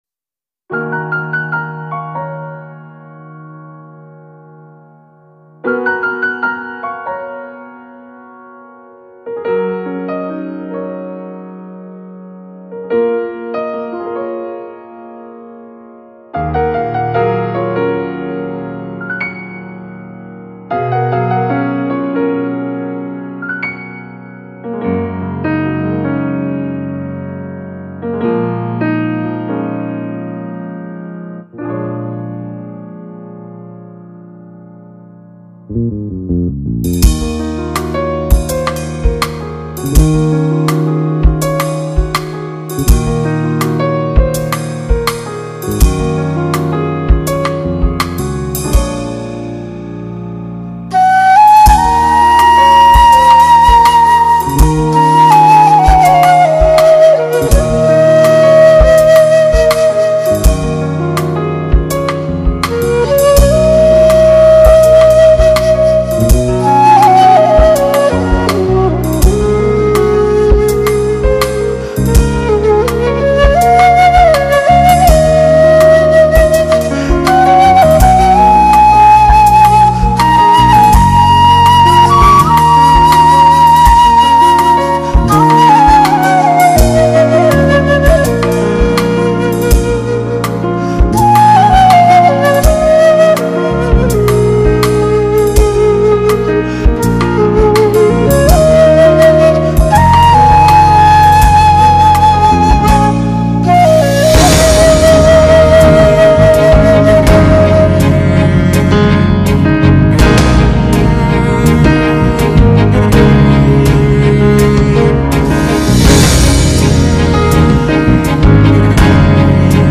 南派笛箫第一人 以一笛十二妙曲 信步幽径小桥上 捻来缤纷红尘事